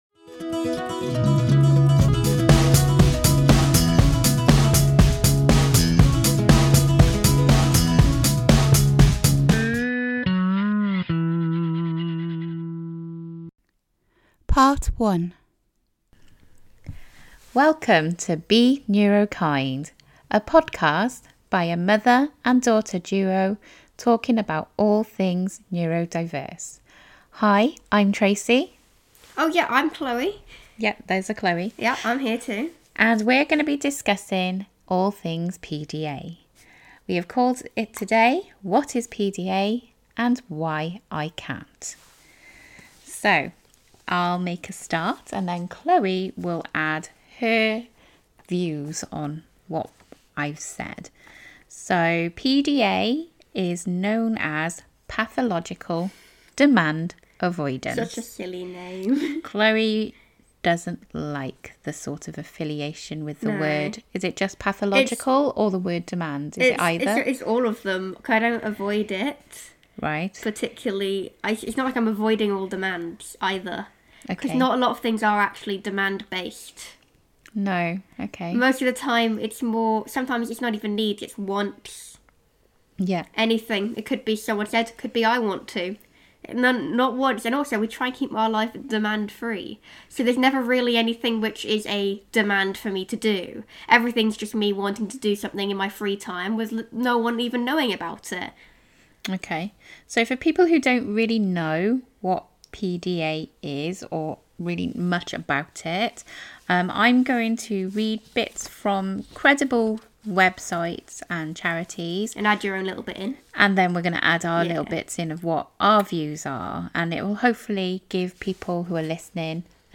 In this episode mother and daughter duo discuss what is PDA (Pathological demand avoidance) Other names that are also recognised as PDA what it means to us and how we experience it from a parent and child prospective.